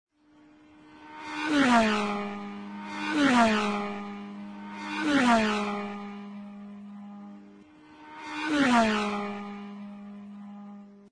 Autos corriendo a alta velocidad V. 1: efectos de sonido gratis
Tipo: sound_effect
Autos corriendo a alta velocidad V1.mp3